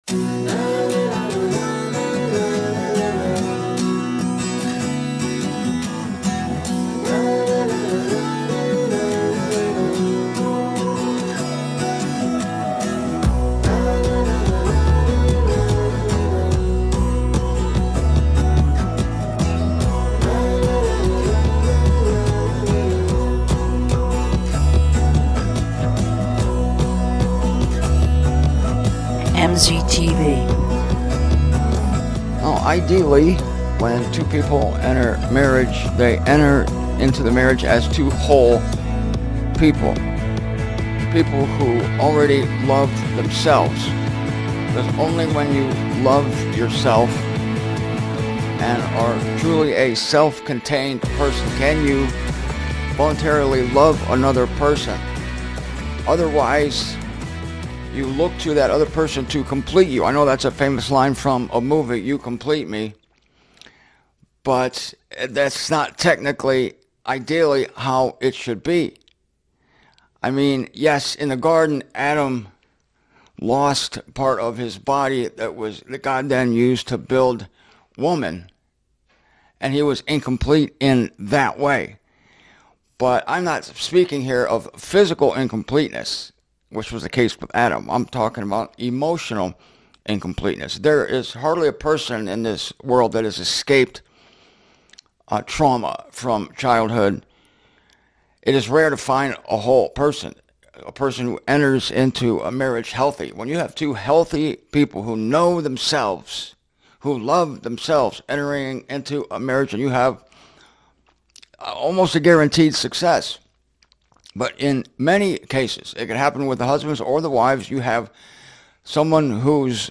I apologize that the sound and video become out of synch in this video.